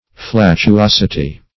Search Result for " flatuosity" : The Collaborative International Dictionary of English v.0.48: Flatuosity \Flat`u*os"i*ty\, n. [Cf. F. flatuosit['e].]